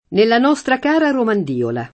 Romandiola [ romand & ola ]